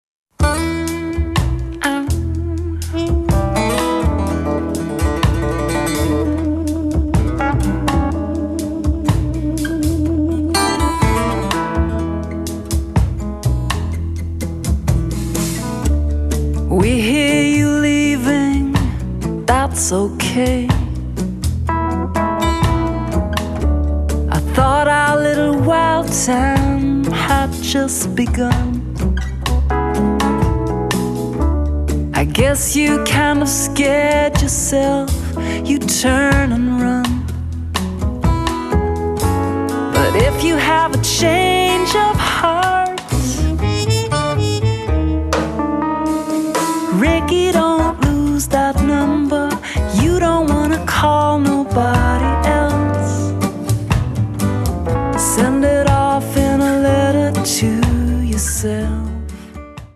vocals, acoustic guitar
acoustic and electric guitars, wurlitzer
trumpet, fluegelhorn
drums, percussion